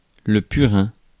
Ääntäminen
France: IPA: /py.ʁɛ̃/